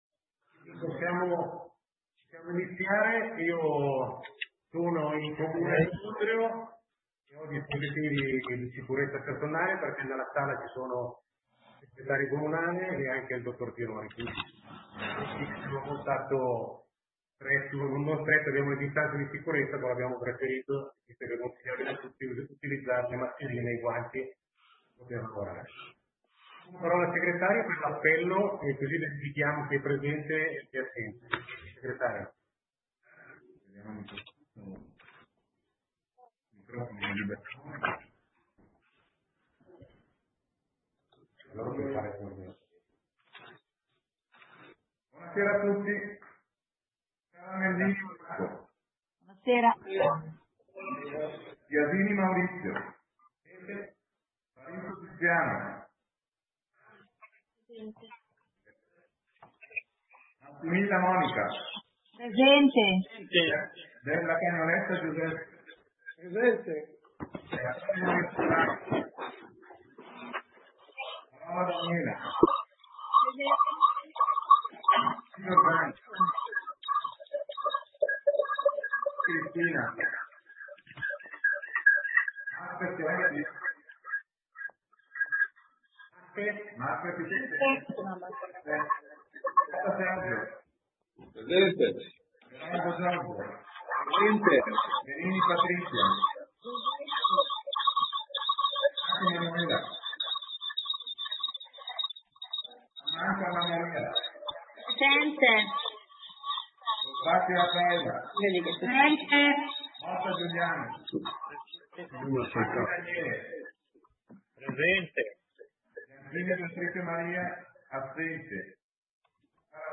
Seduta consiglio comunale del 27 marzo 2020 - Comune di Sondrio